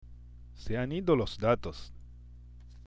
聞き取りにくいかも知れませんね。
hanido と、つながって聞こえるケースも